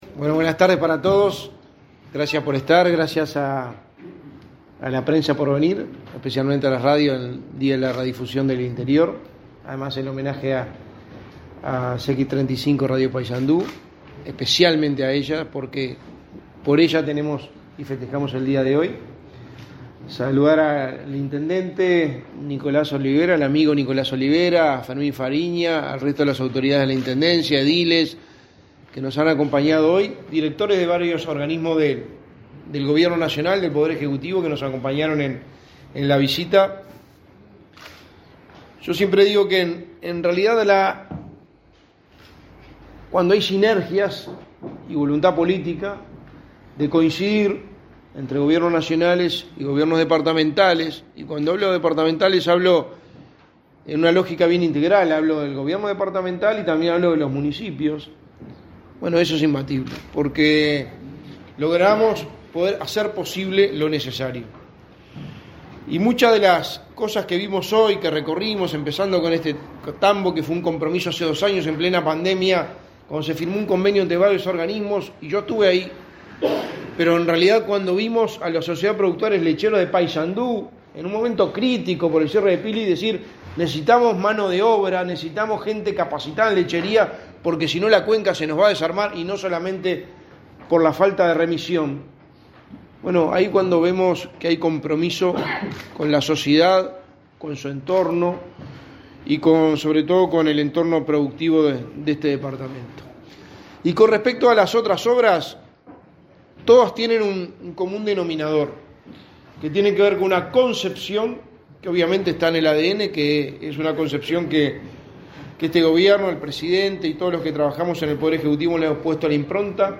Palabras del secretario de la Presidencia, Álvaro Delgado 25/05/2023 Compartir Facebook X Copiar enlace WhatsApp LinkedIn El secretario de la Presidencia, Álvaro Delgado, se expresó en conferencia de prensa, este 25 de mayo, tras finalizar su recorrida por el departamento de Paysandú.